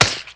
赤手空拳击中肉体－高频-YS070524.wav
通用动作/01人物/03武术动作类/空拳打斗/赤手空拳击中肉体－高频-YS070524.wav
• 声道 單聲道 (1ch)